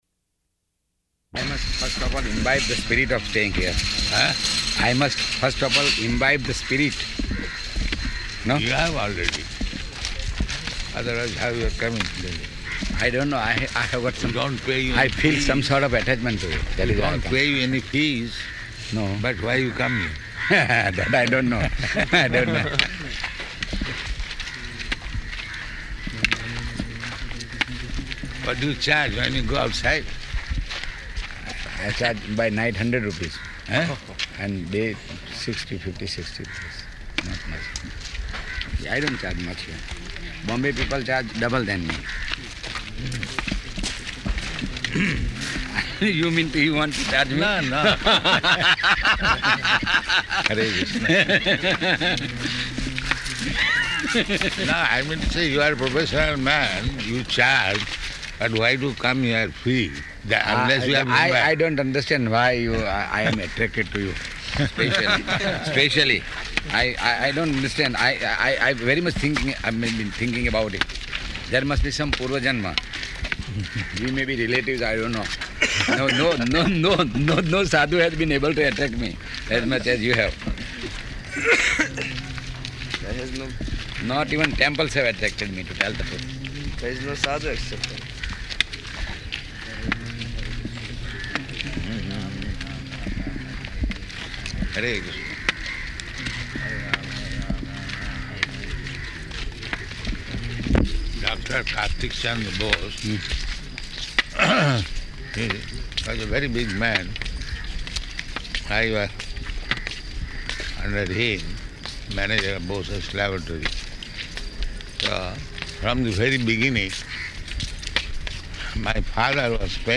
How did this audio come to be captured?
-- Type: Walk Dated: November 20th 1975 Location: Bombay Audio file